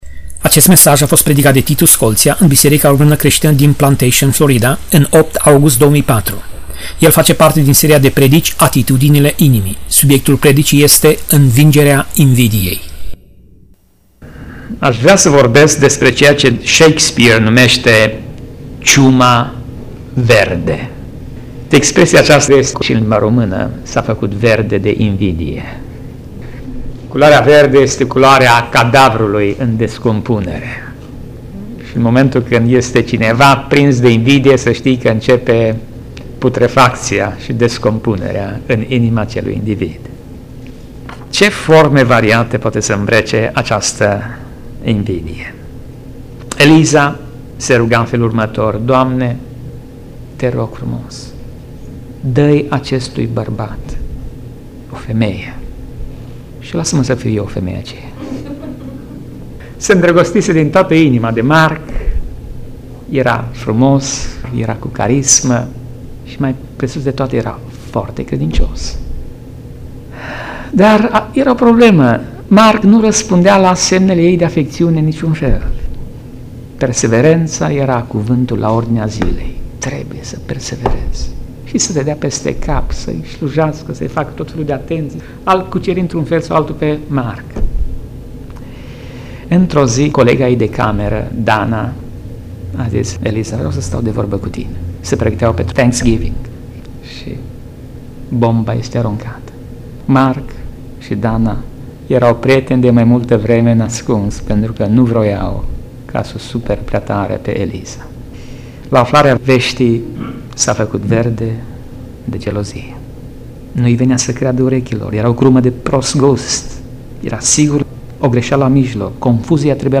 Tip Mesaj: Predica Serie: Atitudinile inimii